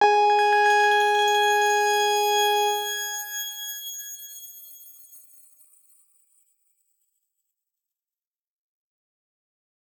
X_Grain-G#4-ff.wav